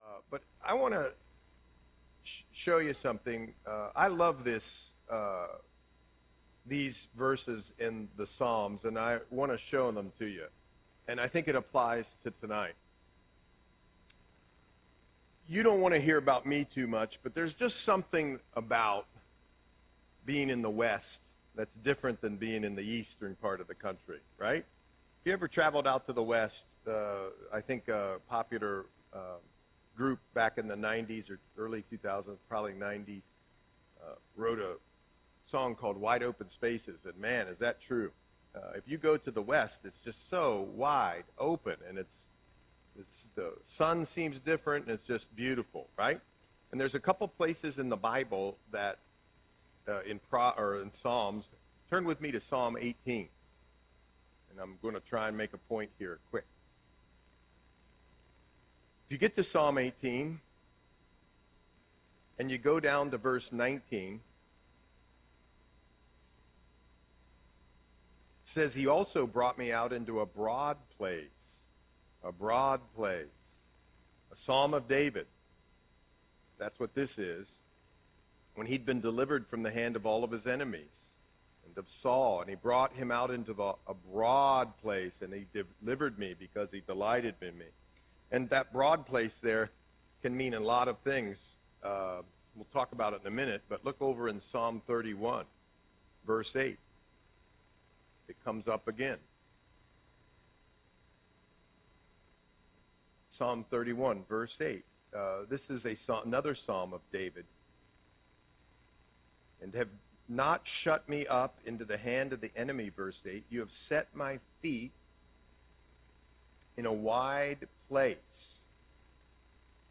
Audio Sermon - December 4, 2024